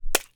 household
Whole Egg Hits Ground